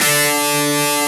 Index of /90_sSampleCDs/Roland LCDP02 Guitar and Bass/GTR_Distorted 1/GTR_Power Chords